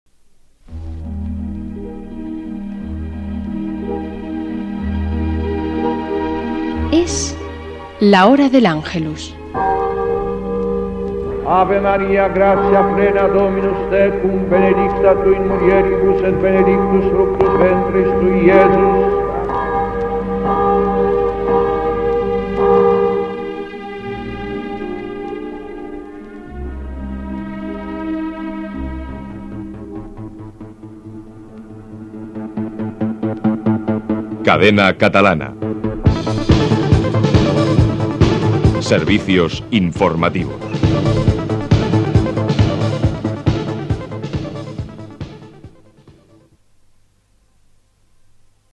"Hora del Ángelus" i careta dels serveis informatius de la Cadena Catalana.
Religió